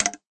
metallic_clack.ogg